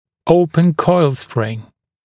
[‘əupən kɔɪl sprɪŋ][‘оупэн койл сприн]открытая витая пружина, открыающая витая пружина